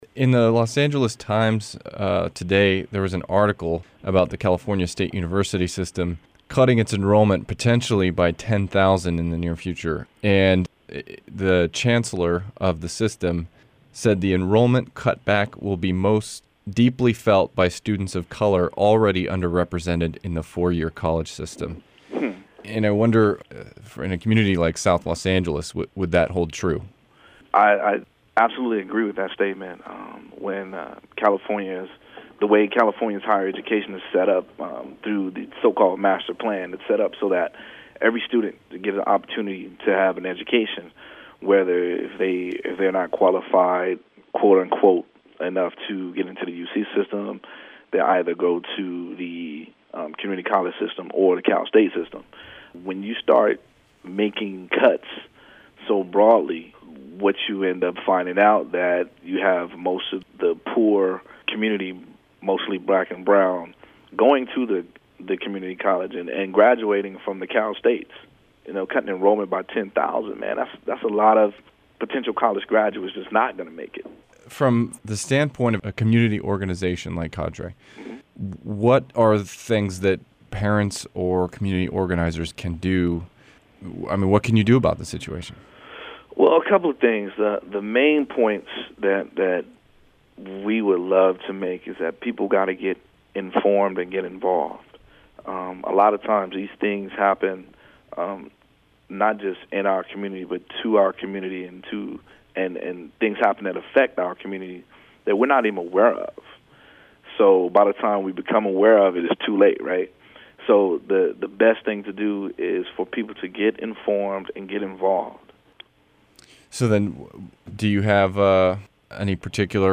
cadreinterview.MP3